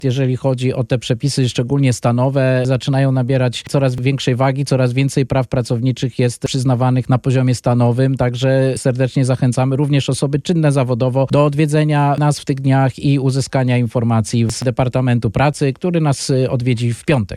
W studiu Radia Deon Konsul Michał Arciszewski serdecznie zaprasza na Dni Poradnictwa ZUS w Chicago, które odbędą się w dniach 15-17 grudnia 2023 r. w siedzibie Związku Narodowego Polskiego (PNA) przy 6100 N Cicero Ave.